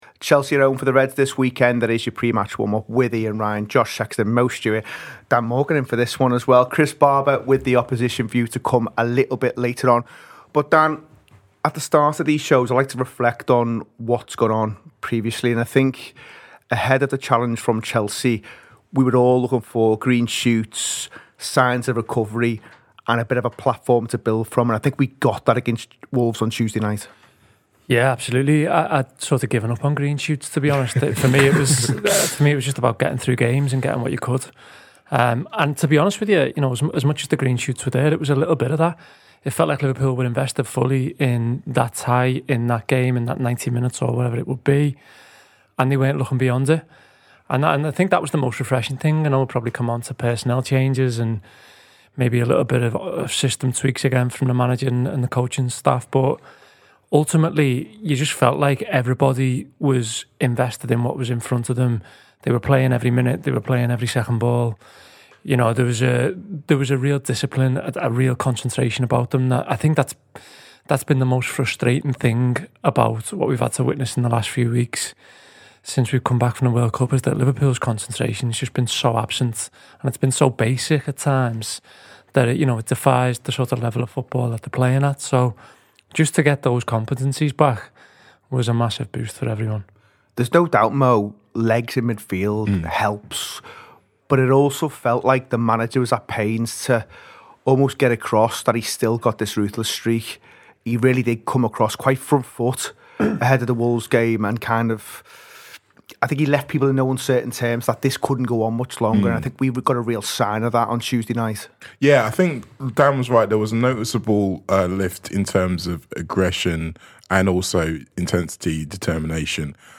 Below is a clip from the show – subscribe for more pre-match on Liverpool v Chelsea…